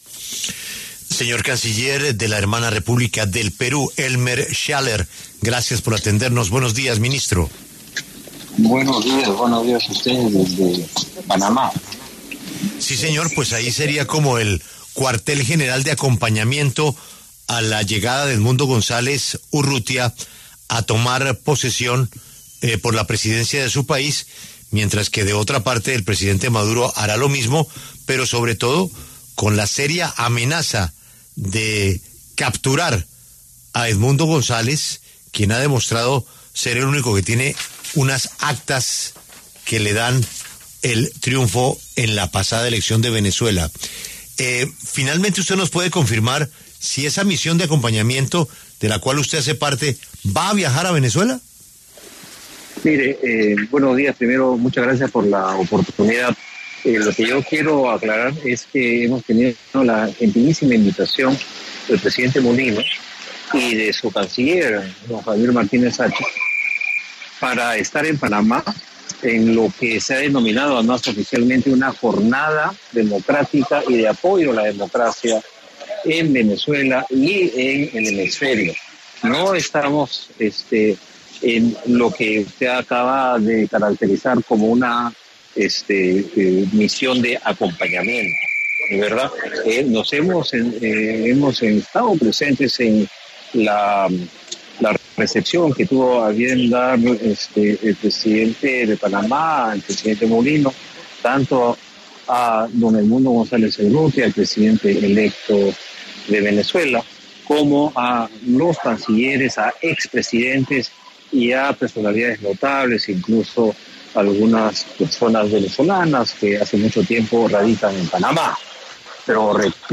El canciller peruano Elmer Schialer habló con La W a propósito de su viaje a Panamá el miércoles 8 de enero para una reunión sobre la defensa de la democracia en Venezuela, convocada por el Gobierno panameño.